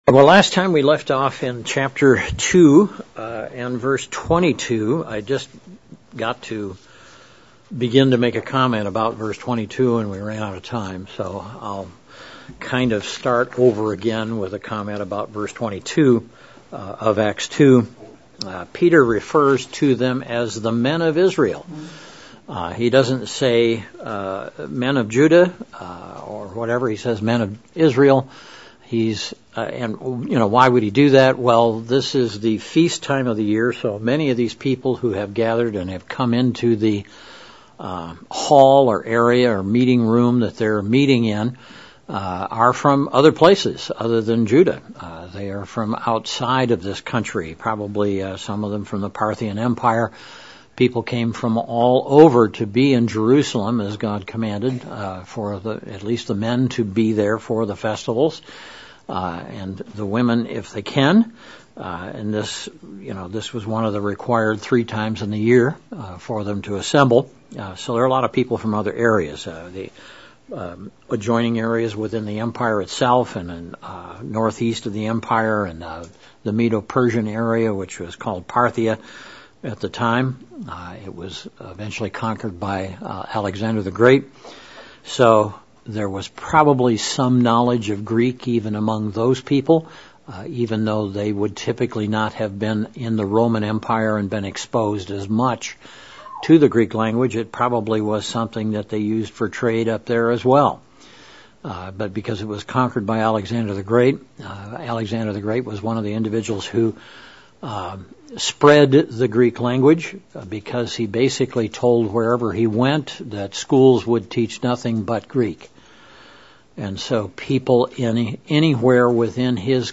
Given in Central Illinois
Acts chapter 2. book of Acts Bible study Studying the bible?